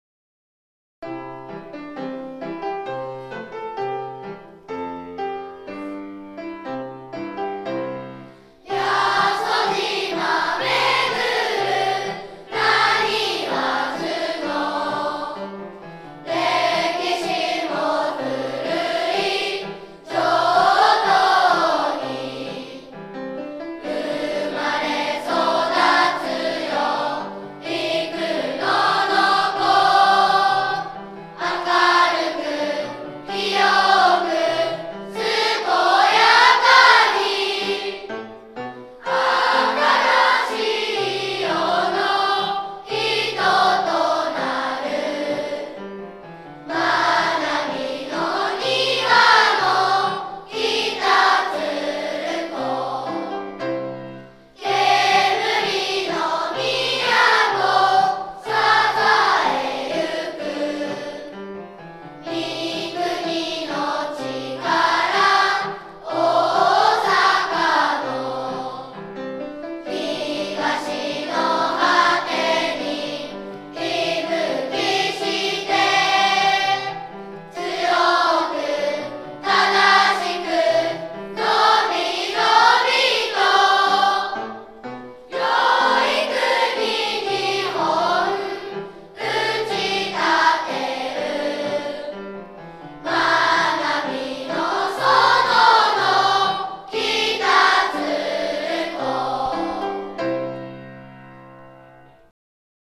北鶴橋小学校 校歌 歌詞 北鶴橋小学校 校歌 歌入り